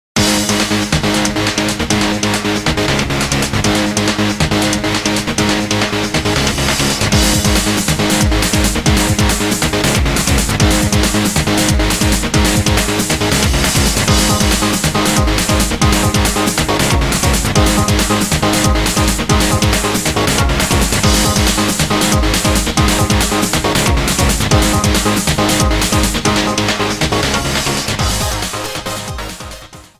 Cut at 30s, fadeout of 3s at the end.